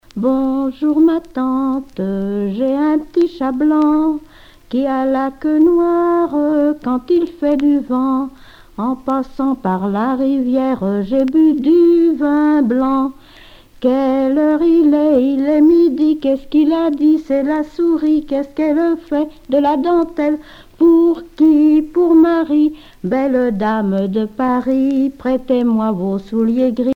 Localisation Cherves
Enfantines - rondes et jeux
enfantine : comptine